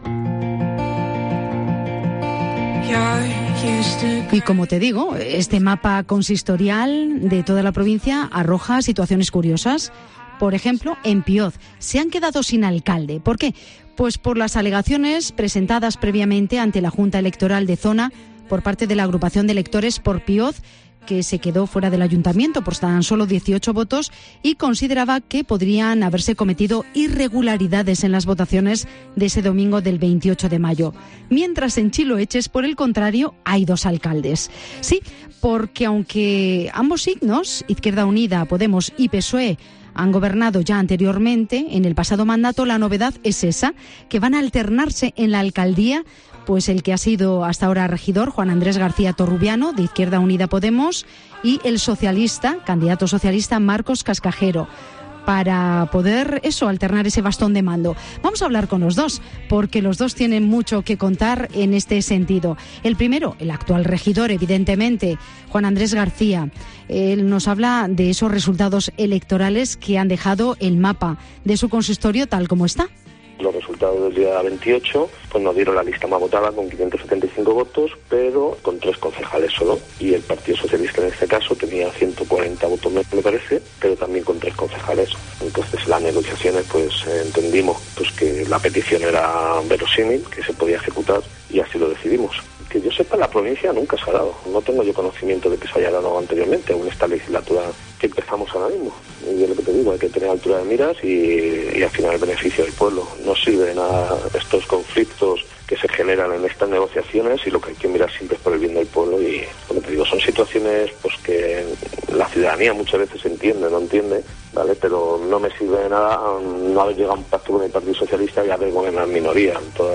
Entrevista a los dos alcaldes de Chiloeches en Mediodía COPE Guadalajara